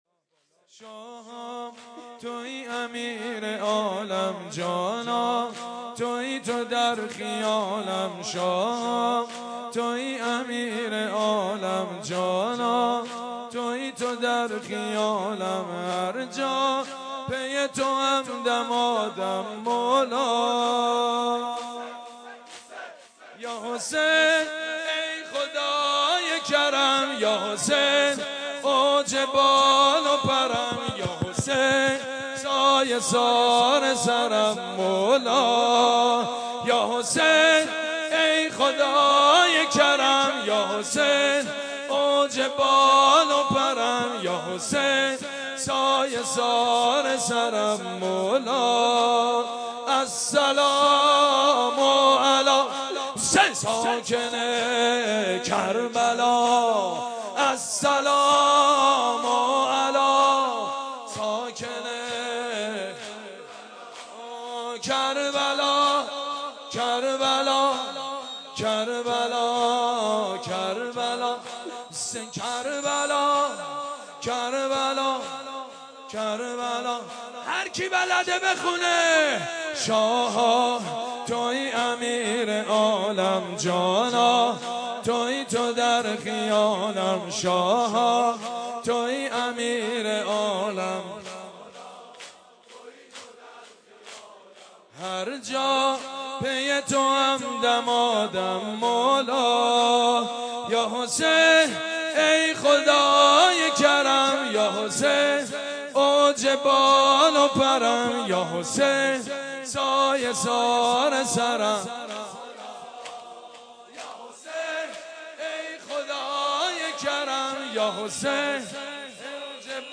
مداحی
شور زیبا در مدح امام حسین